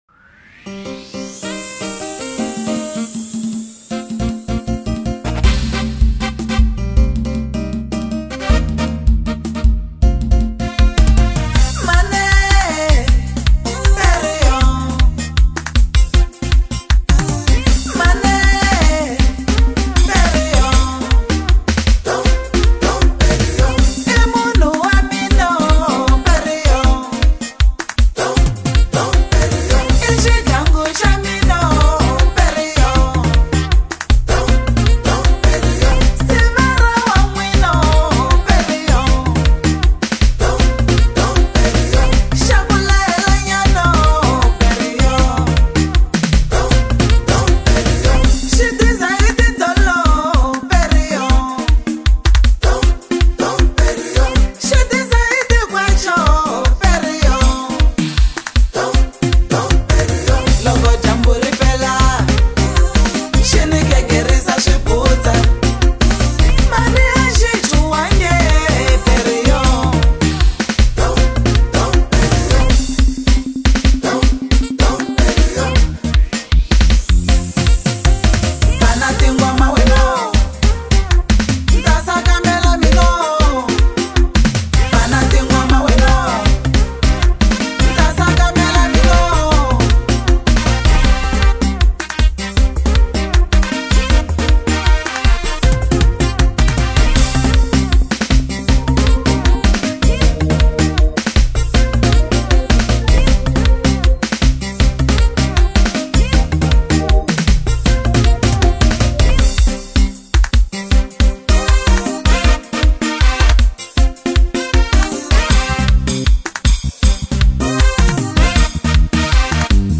Award winning singer